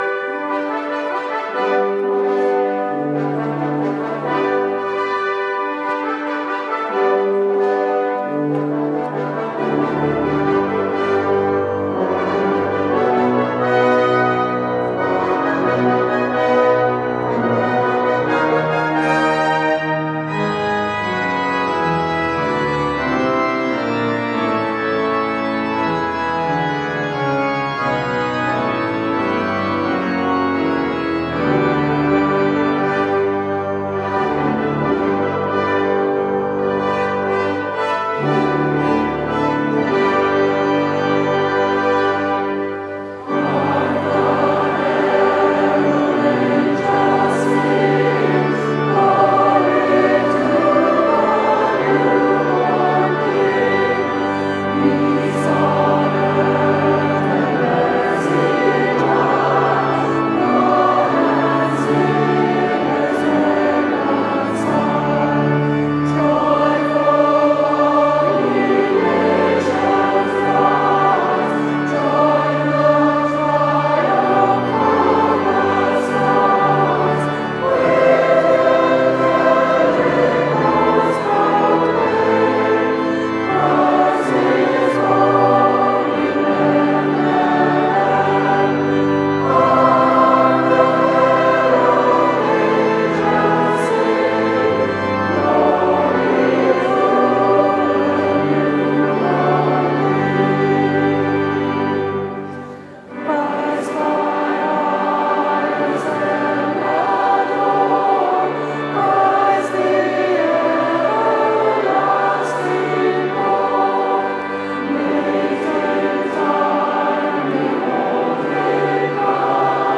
C’est une chorale formée par des chanteurs volontaires de toutes les chorales de la ville (comme la mienne et celle de ma gentille voisine anglaise).
C’est aussi un concert où les enfants sont invités, et tout le monde peut participer aux chants les plus connus (les paroles étaient dans le programme).